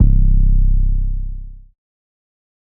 808_Oneshot_Able_C
808_Oneshot_Able_C.wav